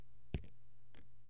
水滴在纸上 " 水在纸上03
描述：滴在纸上。
声道立体声